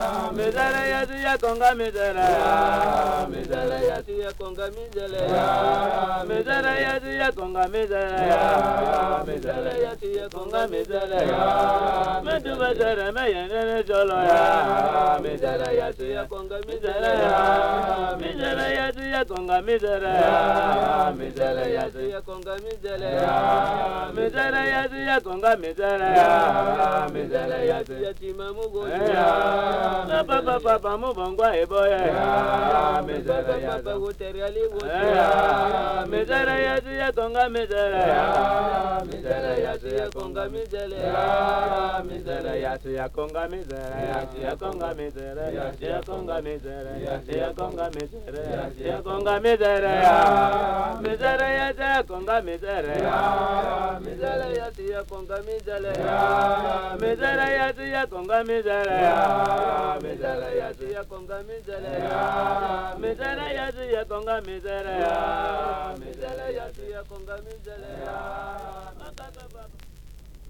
06_bam109_miseria_chant_des_pagayeurs_de_l_ogooue.mp3